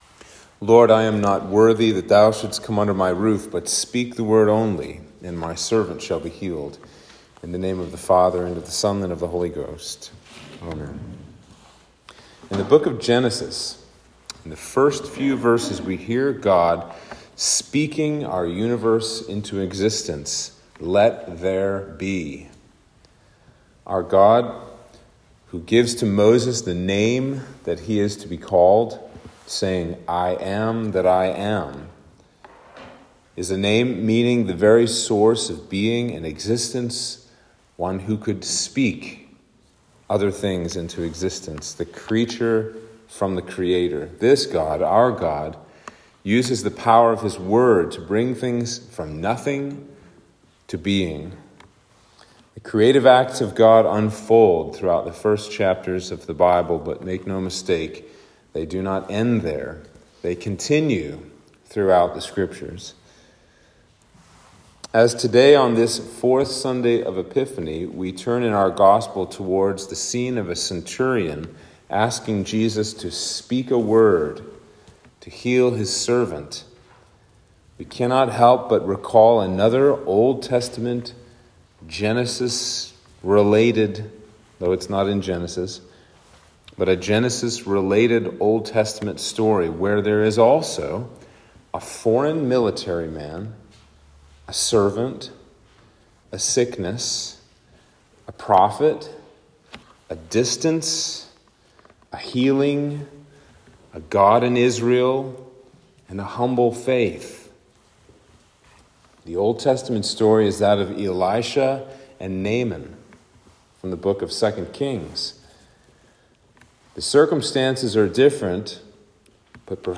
Sermon for Epiphany 4